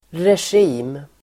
Uttal: [resj'i:m]